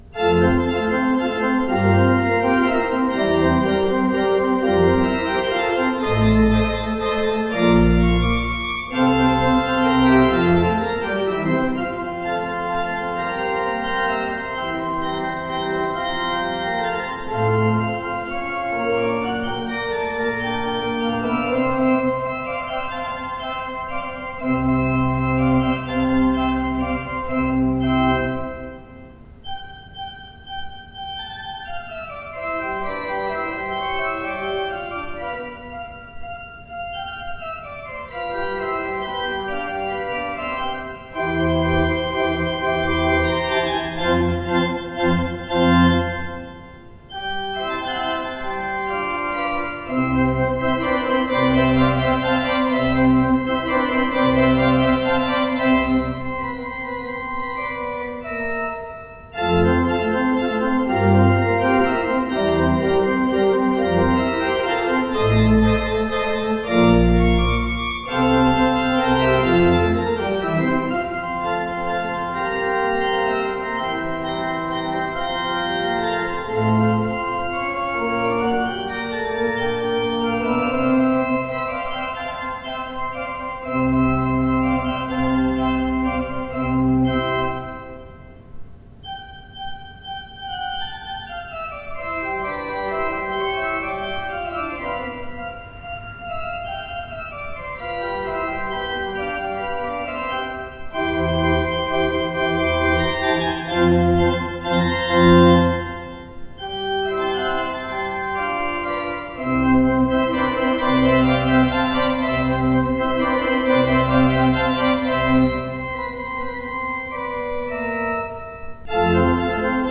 Postludes played at St George's East Ivanhoe 2013
The sound files listed below are not live service recordings due to obvious logistic difficulties. The recordings are taken from rehearsal tapes made in the week prior to the service in question.